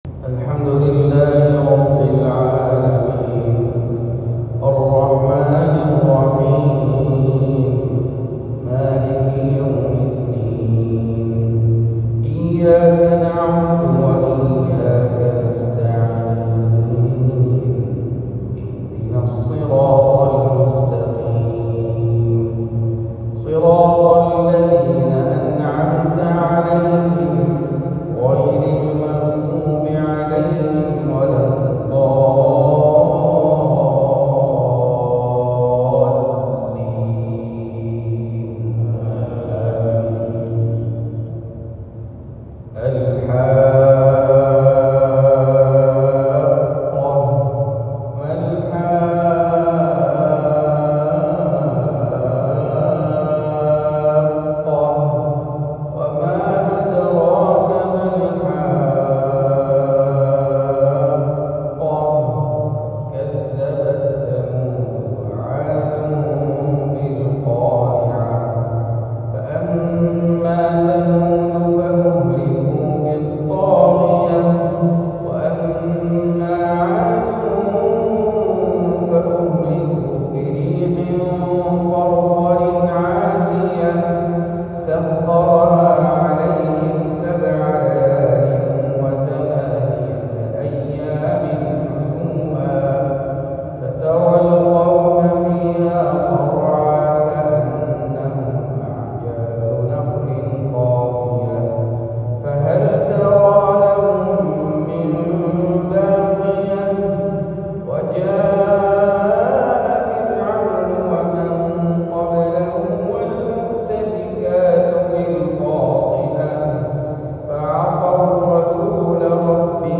فجرية بجامع الميقات